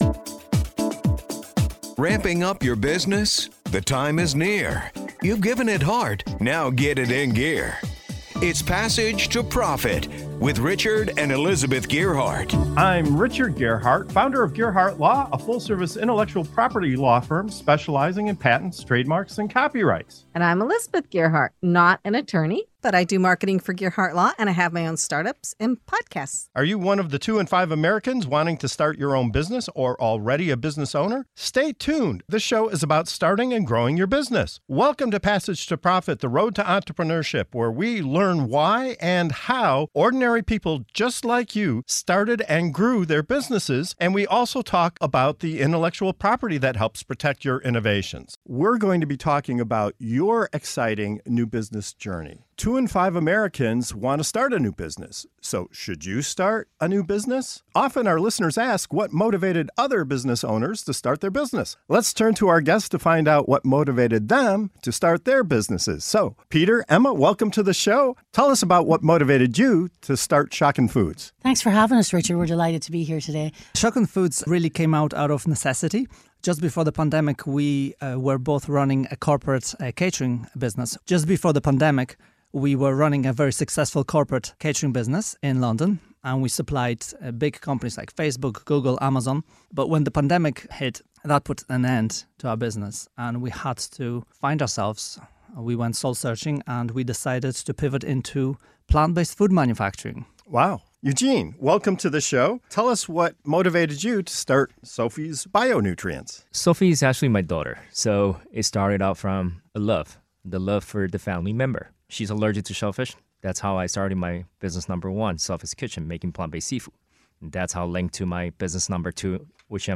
Explore the diverse paths to entrepreneurial success and discover what fuels the drive to start something new.